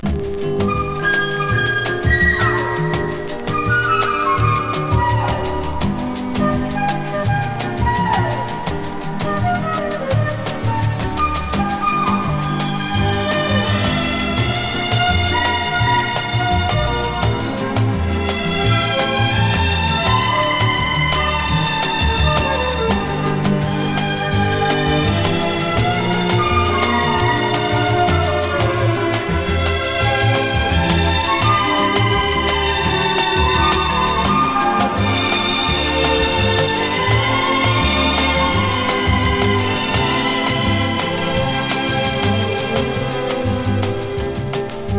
流麗で楽しさいっぱい、時折ドラマチックで楽しく爽やかな1枚。"
Pop, Easy Listening　UK　12inchレコード　33rpm　Stereo